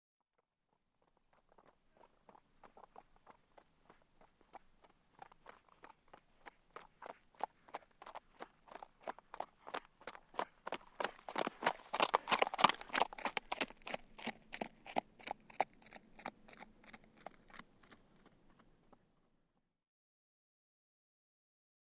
دانلود آهنگ اسب 90 از افکت صوتی انسان و موجودات زنده
جلوه های صوتی
دانلود صدای اسب 90 از ساعد نیوز با لینک مستقیم و کیفیت بالا
برچسب: دانلود آهنگ های افکت صوتی انسان و موجودات زنده دانلود آلبوم انواع صدای شیهه اسب از افکت صوتی انسان و موجودات زنده